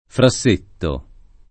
[ fra SS% tto ]